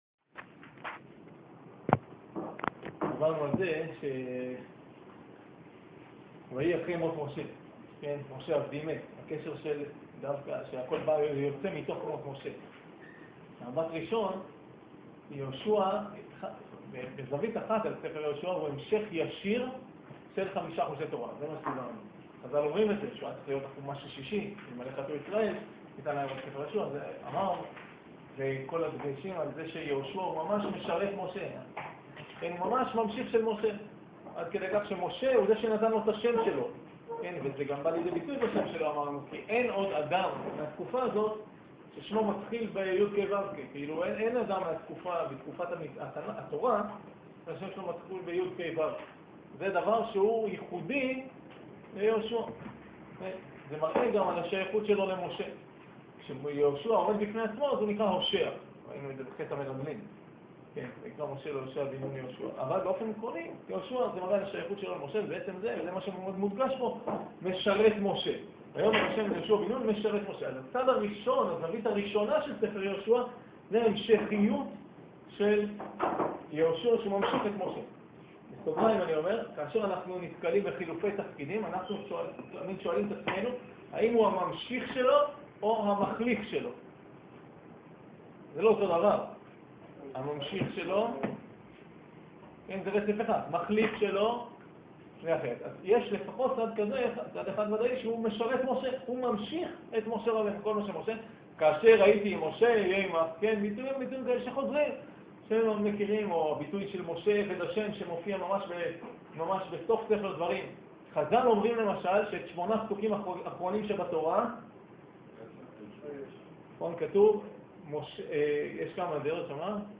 שיעור פרק א' המשך